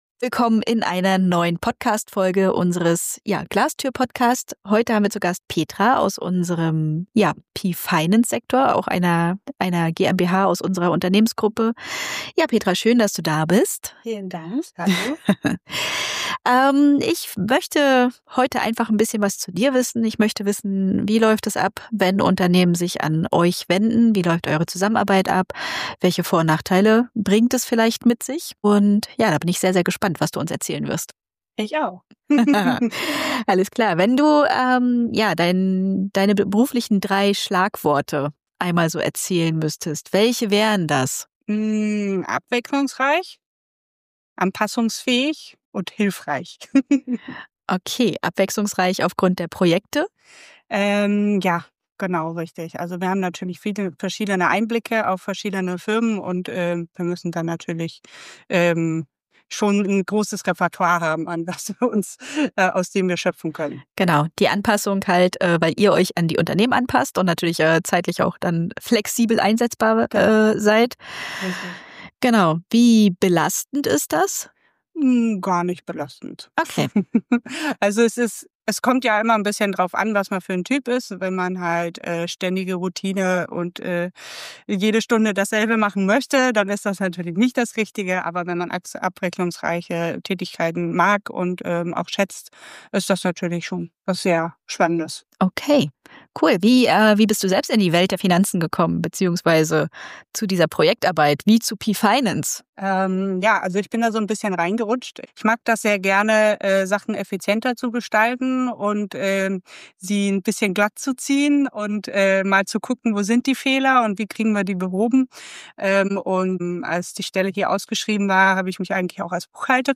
Ein ehrliches Gespräch über Zahlen, Vertrauen – und den Mut, Verantwortung abzugeben.